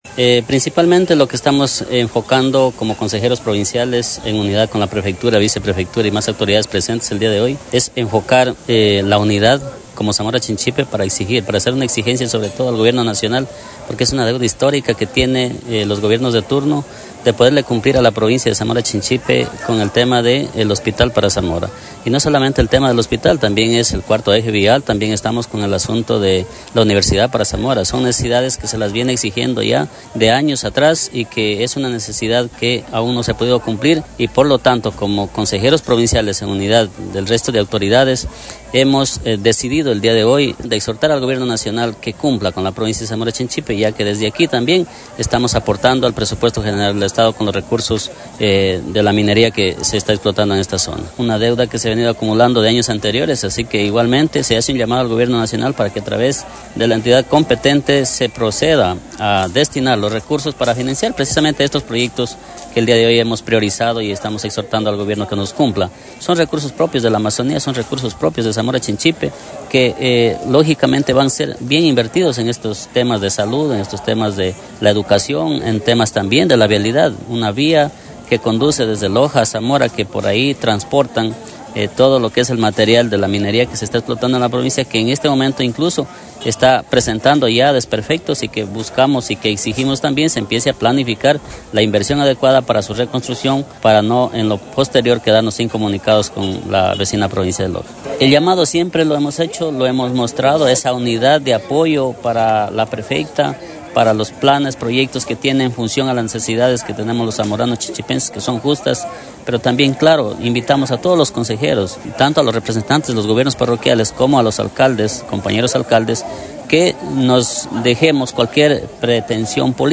FRANCISCO CORDERO, ALCALDE NANGARITZA
FRANCISCO-CORDERO-ALCALDE-NANGARITZA.mp3